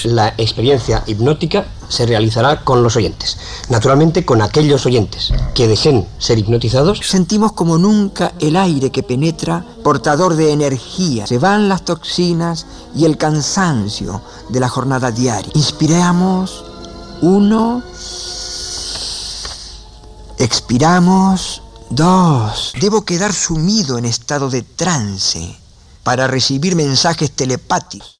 Final de l'experiència i informació des dels estudis de Madrid i Sevilla de com ha anat l'experiència hipnòtica per ràdio.
Informatiu